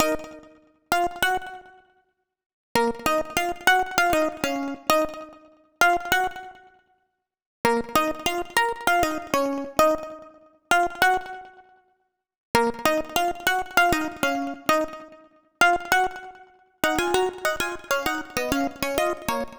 Hands Up - Slapped String.wav